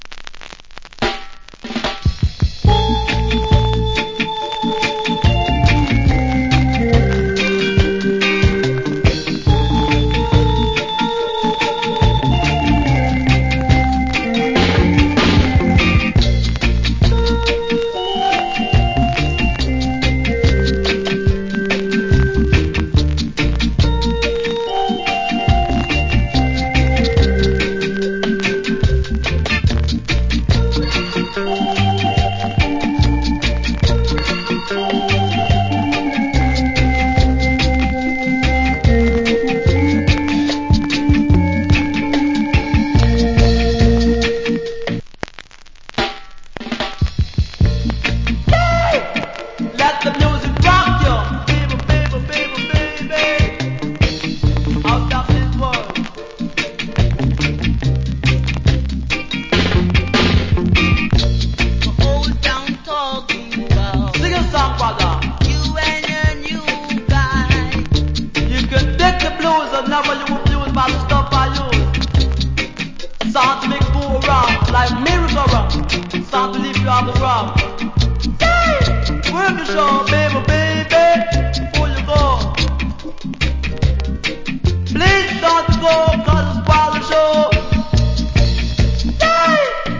Cool Organ Reggae.